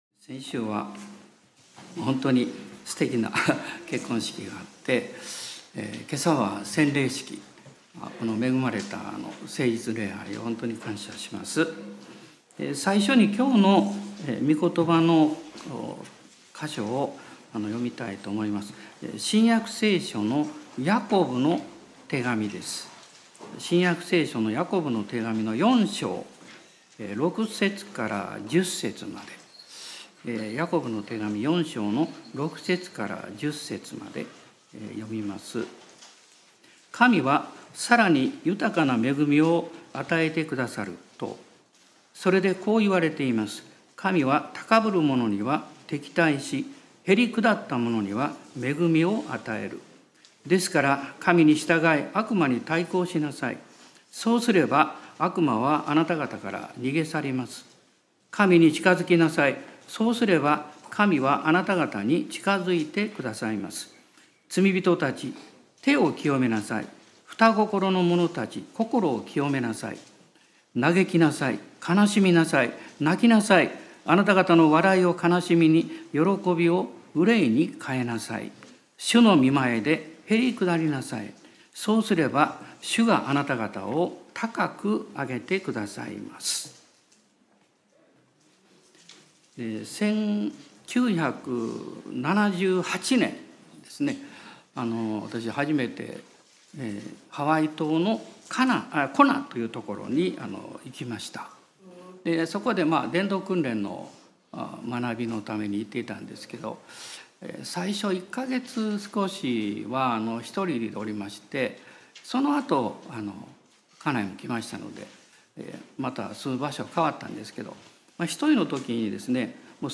2024年1月1日 元旦礼拝